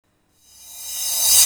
Ew Rev Crash.wav